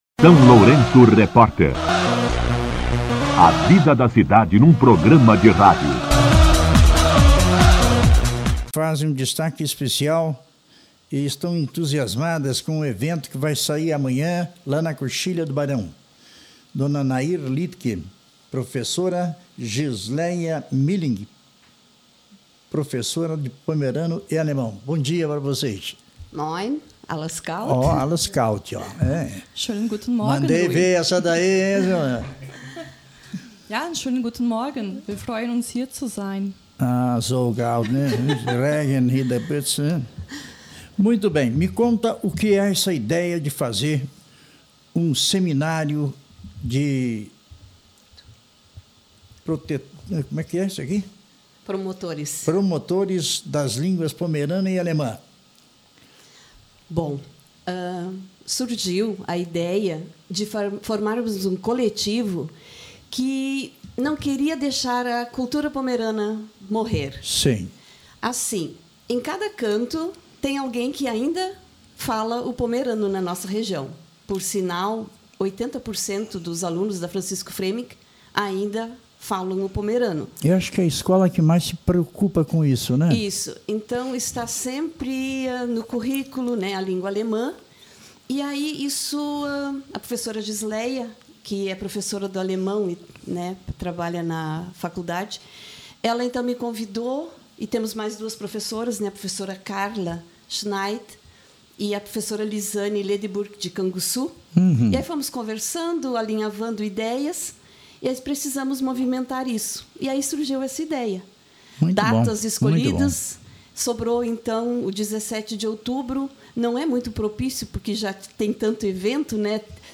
Entrevista
entrevista-pomerana-1610.mp3